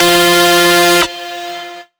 Index of /99Sounds Music Loops/Instrument Oneshots/Leads